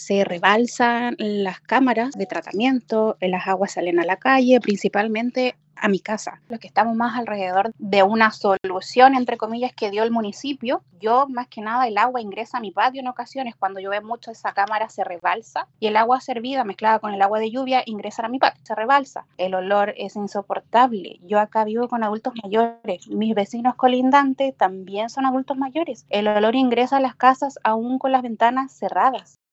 vecina del sector
vecina-pichil-1.mp3